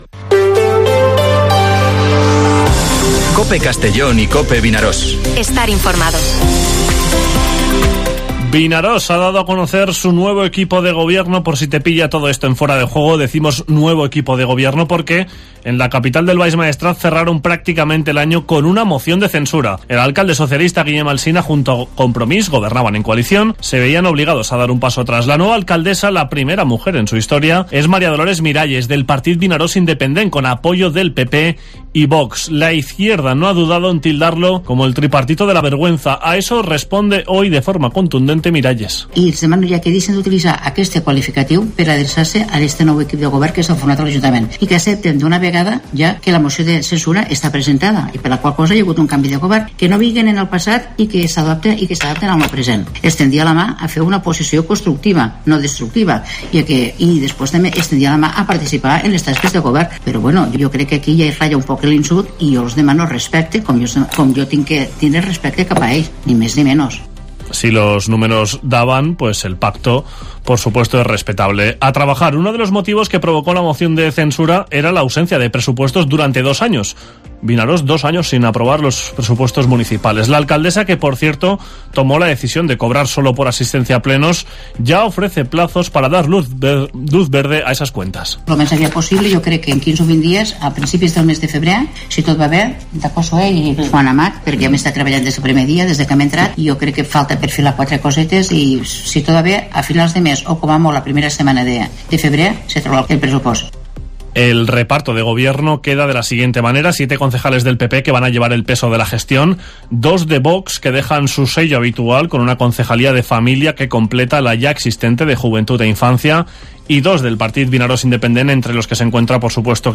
Esta mañana la alcaldesa, acompañada por el vicealcalde, Juan Amat y el tercer teniente de alcalde, Josué Brito, ha dado a conocer el nuevo cartapacio municipal.